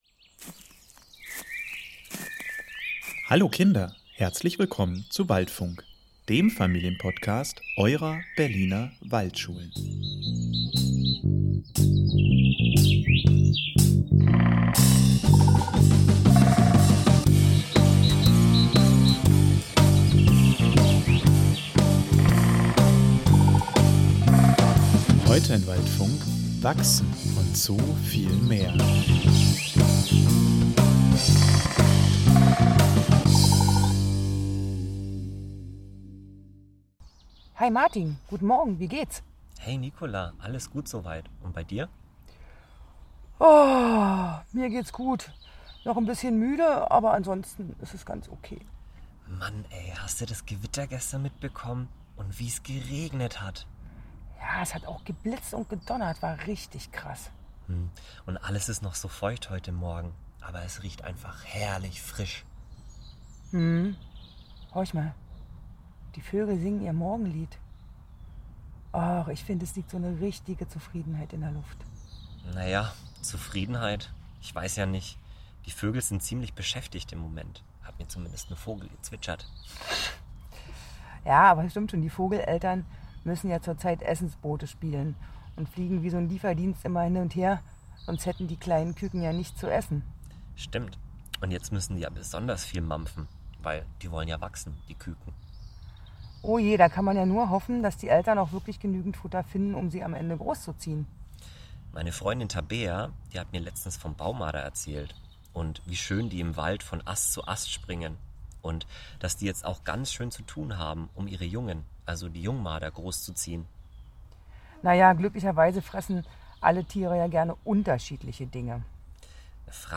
Im Tiergarten treffen sie sich mit Experten aus den Waldschulen, die jede Menge spannende Dinge berichten. Am Ende ihres Tages sitzen die beiden an einem gemütlichen Lagerfeuer und lassen alles Erlebte auf sich wirken.